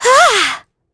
Isaiah-Vox_Attack4.wav